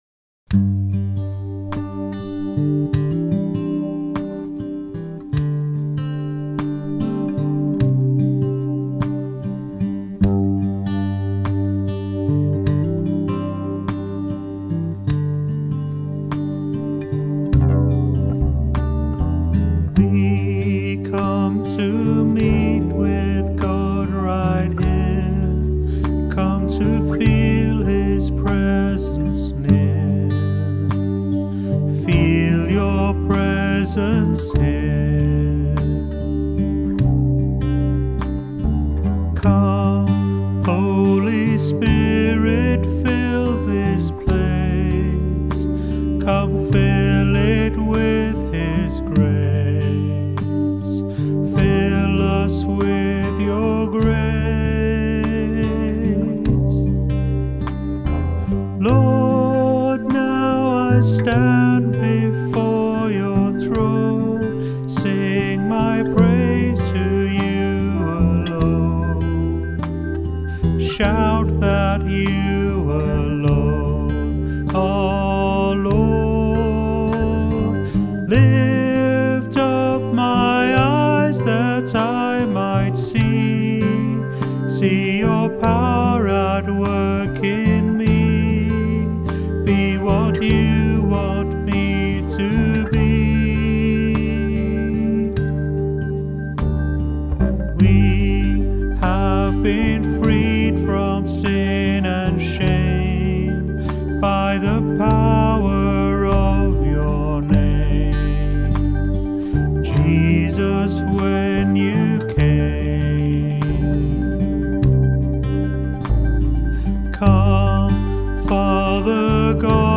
• Praise